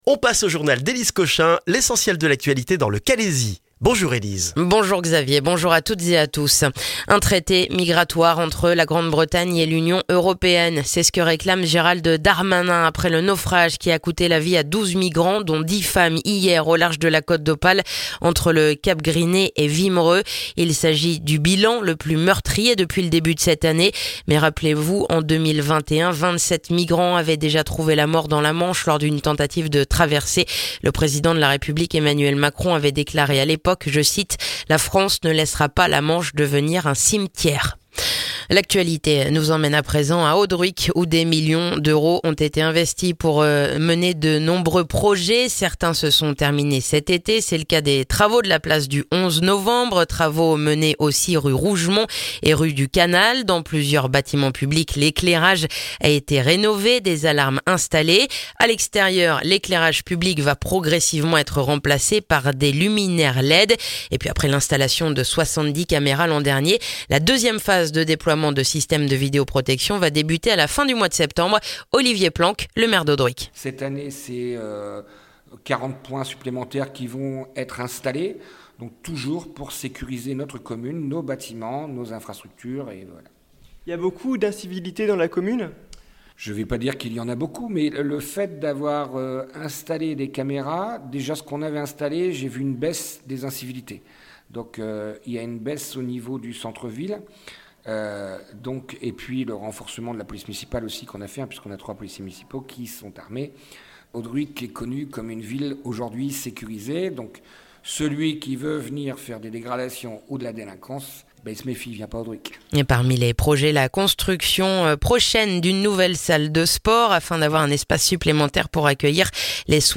Le journal du mercredi 4 septembre dans le calaisis